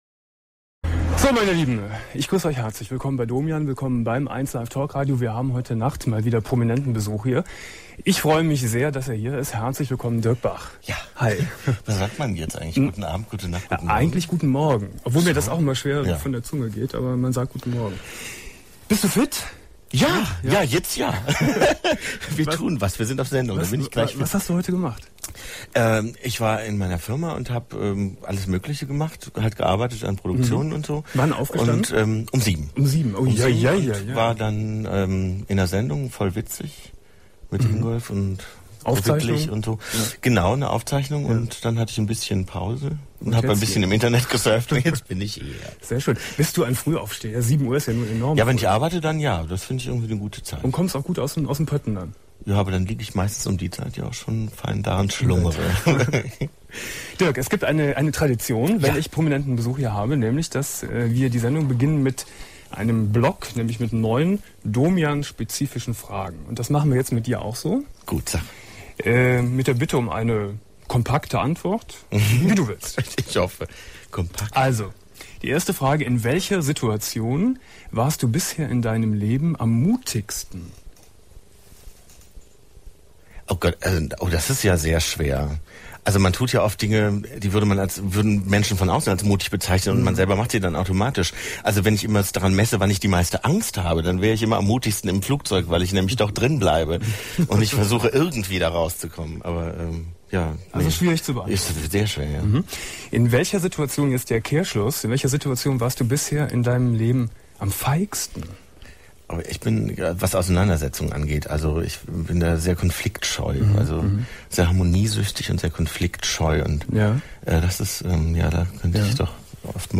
08.12.2000 Domian Studiogast: Dirk Bach ~ Domian Talkradio - Das Archiv Podcast